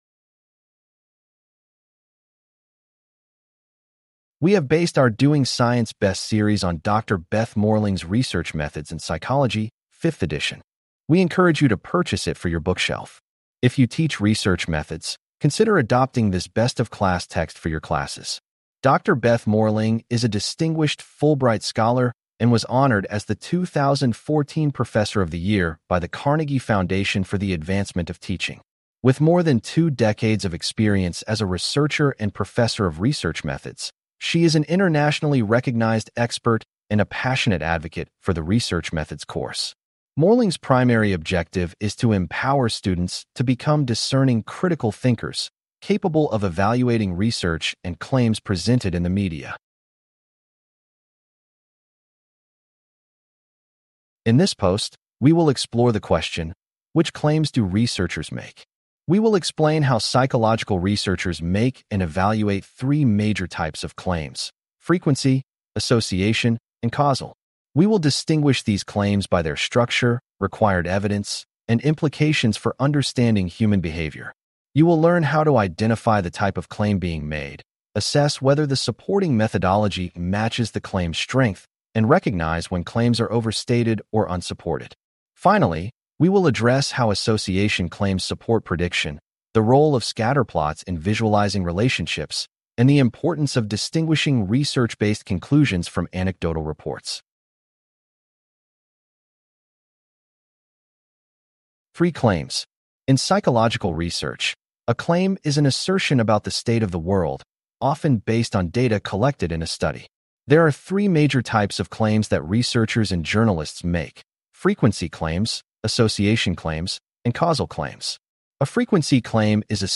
CLICK TO HEAR THIS POST NARRATED We will explain how psychological researchers make and evaluate three major types of claims: frequency, association, and causal.